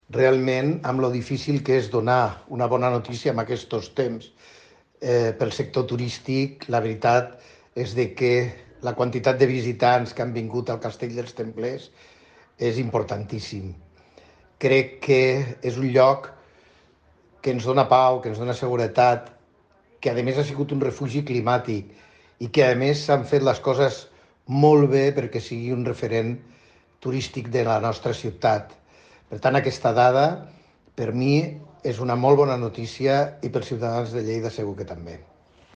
Tall de veu Paco Cerdà